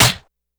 Snare_30.wav